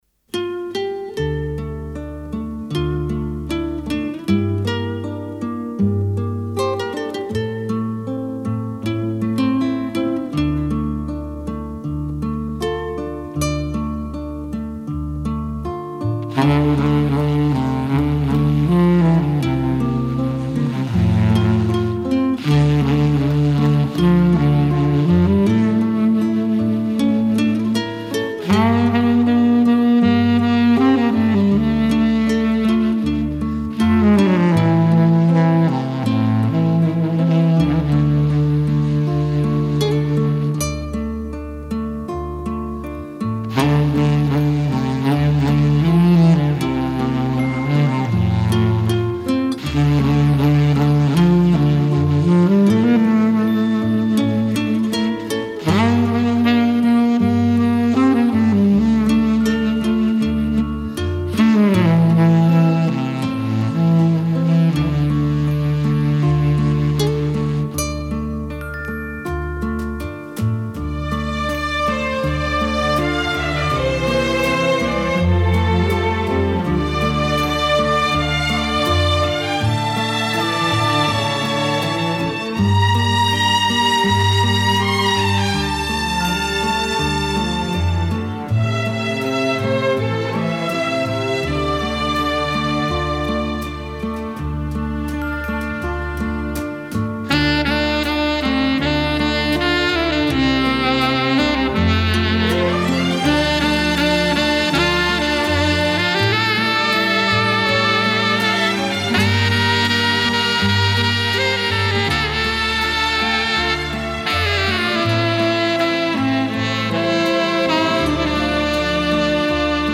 除第9集是欧美音乐外其它是日本音乐〔包括流行，民谣，演歌〕。